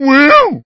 Play, download and share Moblin gets hooked original sound button!!!!